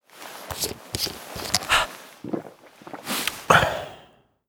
vodka_use.ogg